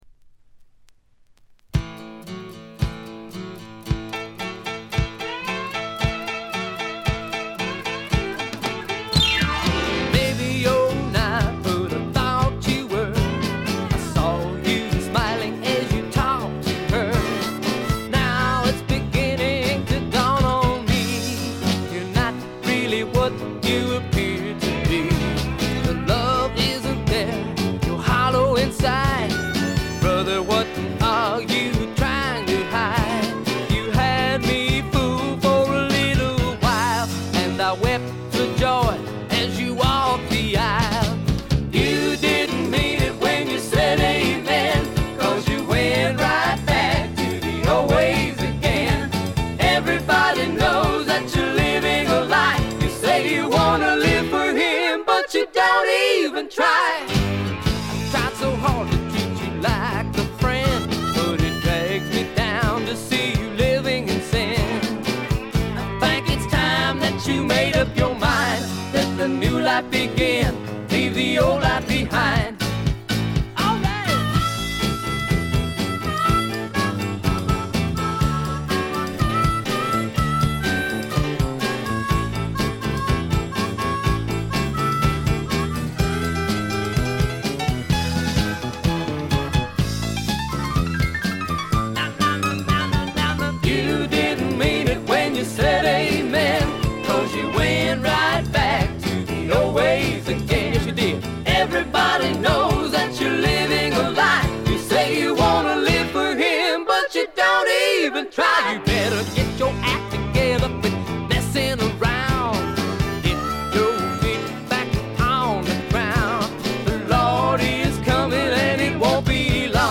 部分試聴ですがほとんどノイズ感無し。
クリスチャン・ミュージックの男女混成グループ
試聴曲は現品からの取り込み音源です。